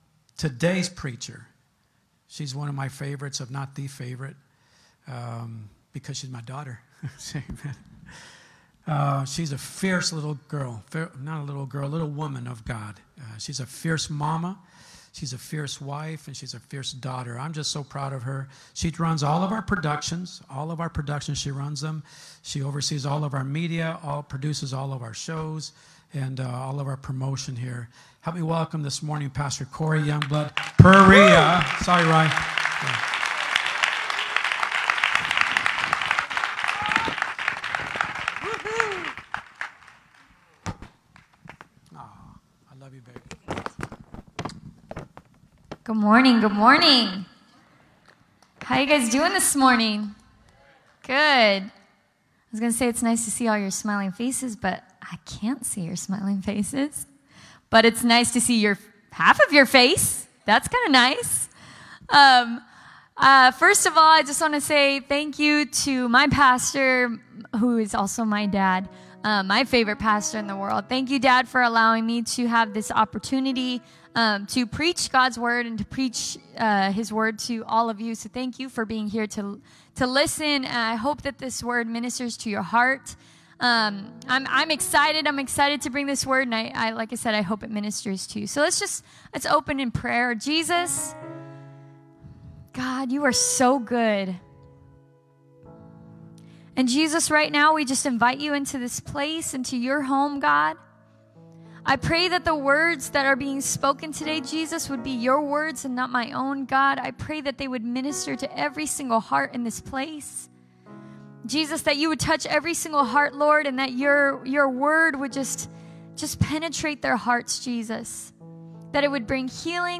Sermons | CWC LIFE Manteca CA